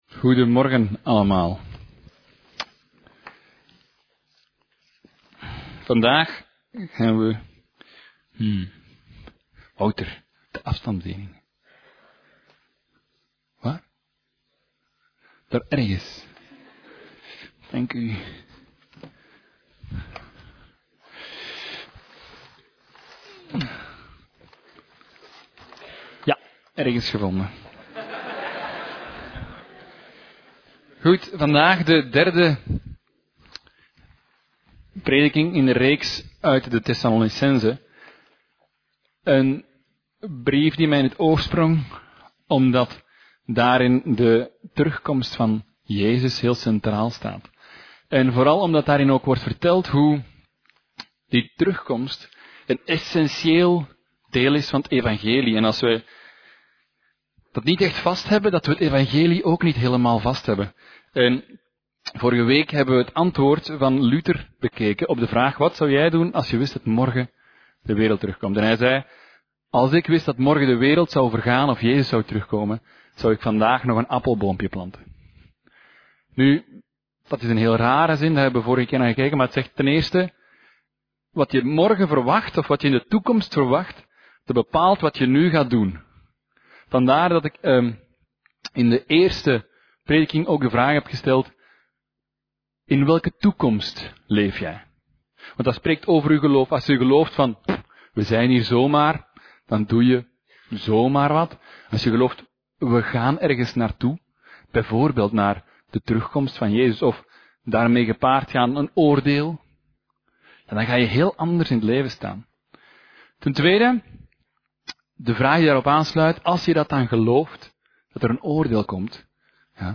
Preek: En nu? (deel3) - Levende Hoop